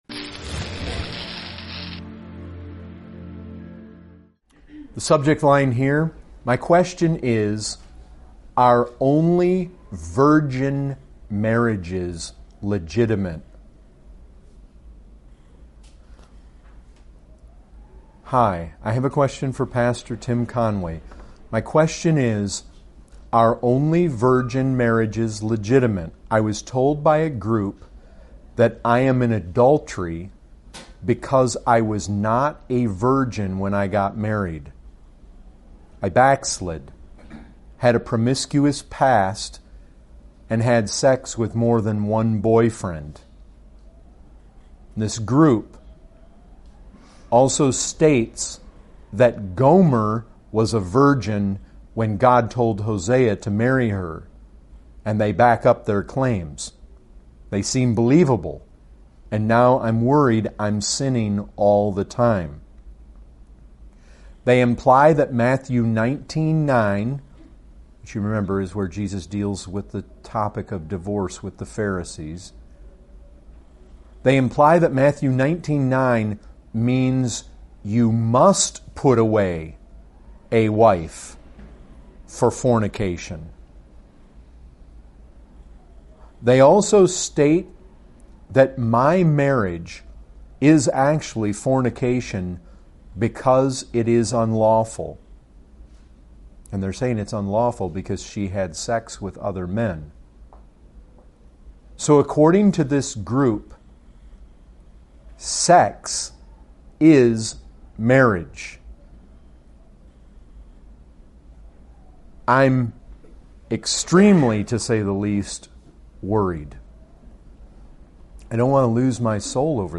Questions & Answers